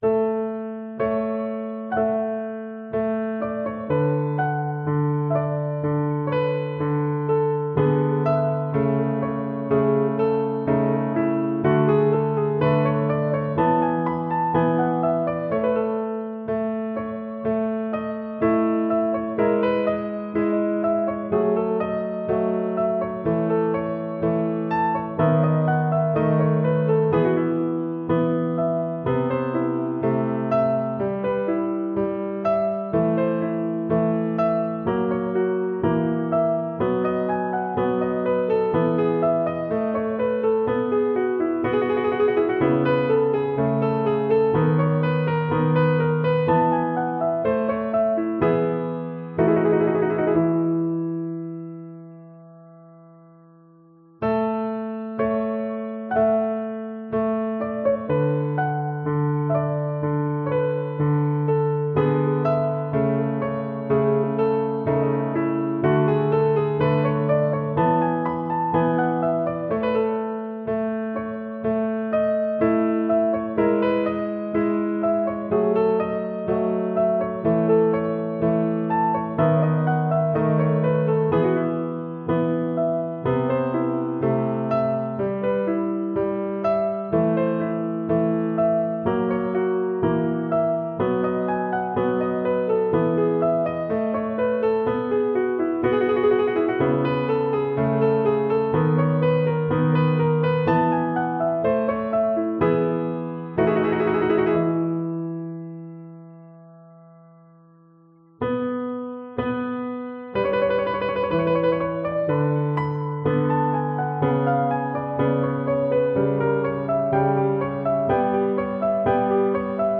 • Duet (Violin / Viola)